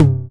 TR 909 Tom 02.wav